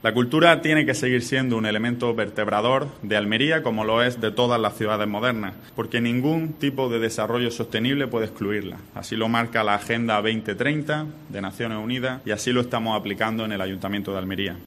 El alcalde de Almería, Ramón Fernández Pacheco, informa sobre el proyecto 'Camina'